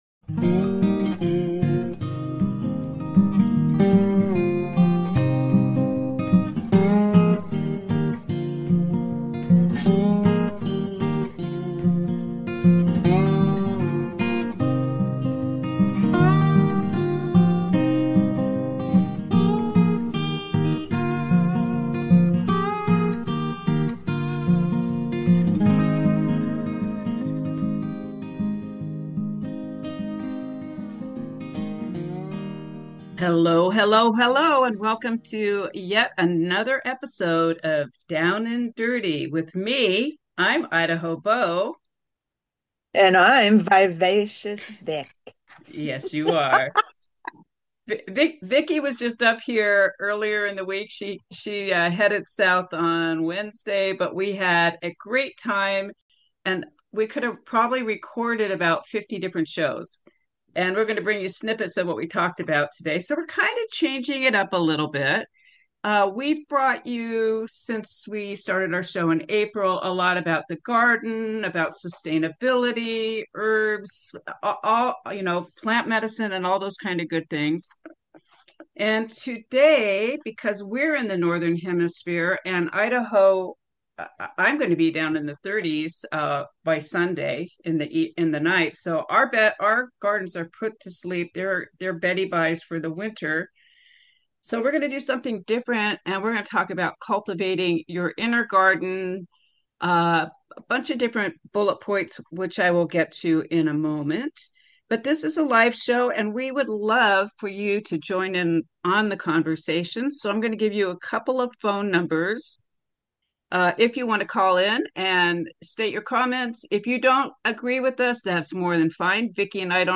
Talk Show Episode
We will be taking calls and are eagerly interested in your opinions, musings and thoughts.